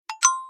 chat_notify.77f6ff11.mp3